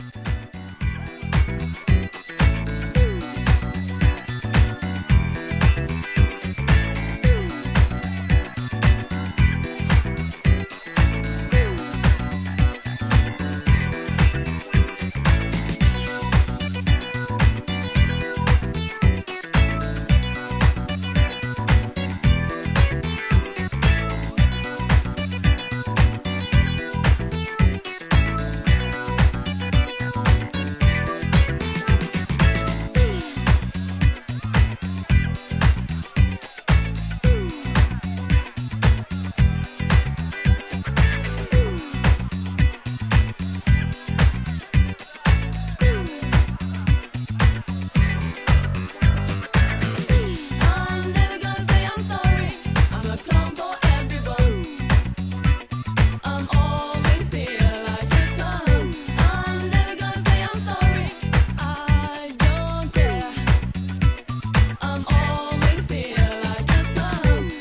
1996年のダンサブル且つキャッチーな人気ナンバー!!